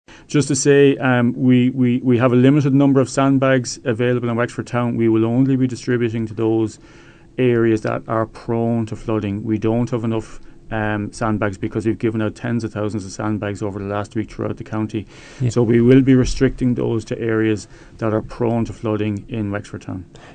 CEO of the council Eddie Taaffe said the bags are limited